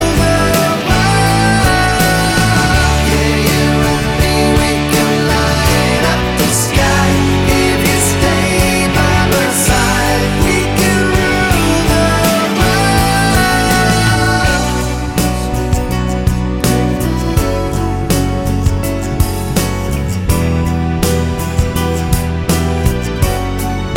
No Main Electric Guitar Pop (1990s) 4:01 Buy £1.50